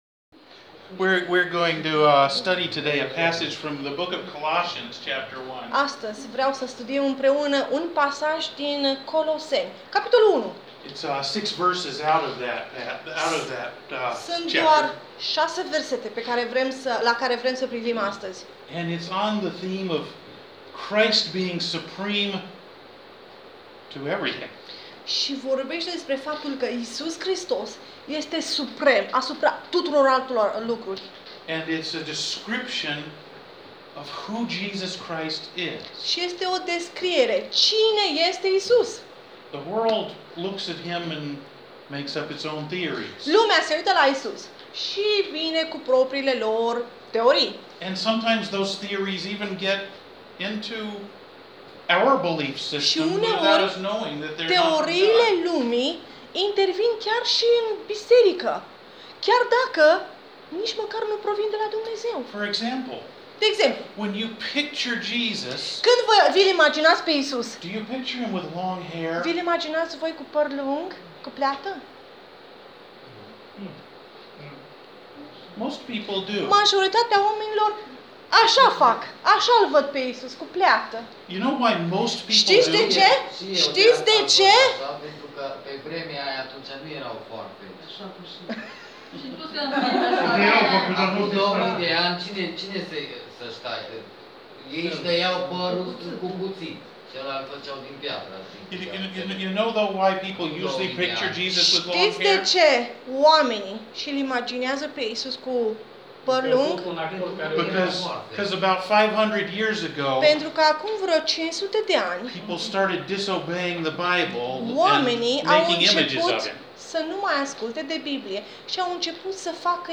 Coloseni 1 – Sermon Audio | Biserica Harul Domnului